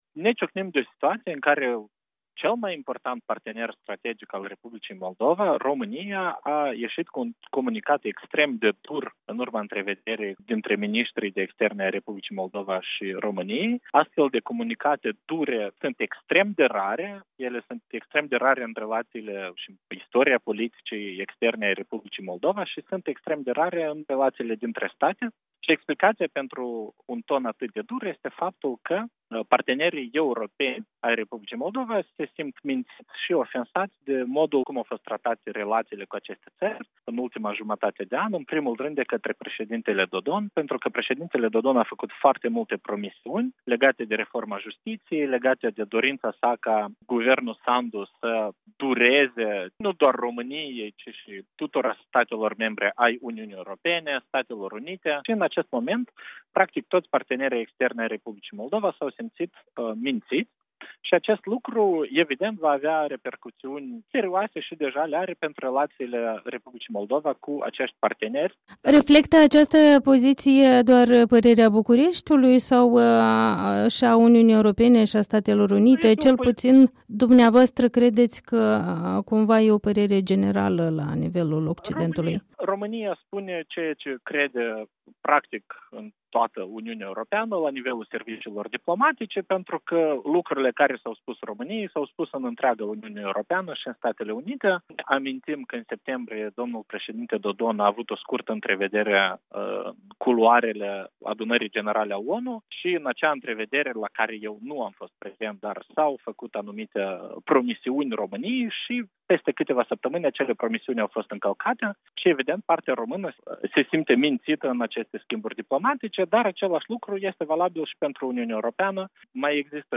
Interviu cu ex-ministru de externe, Nicu Popescu